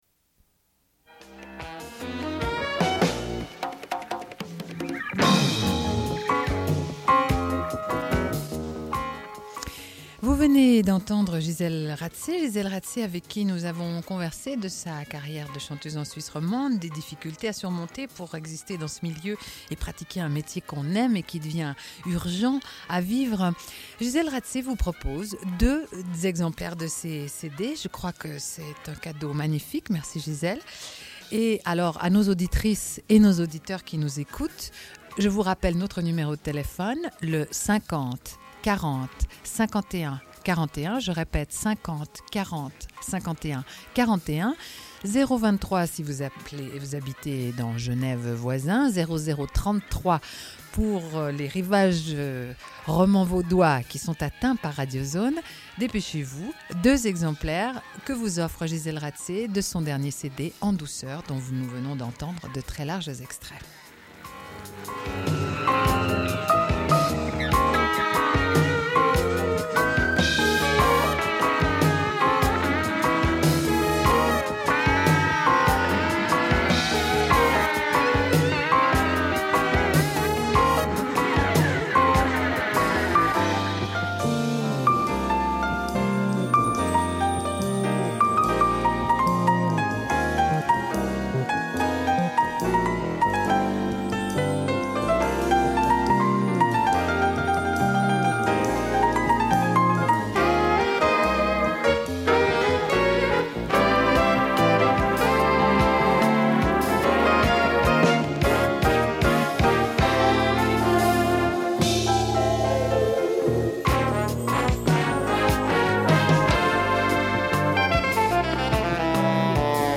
Une cassette audio, face A31:13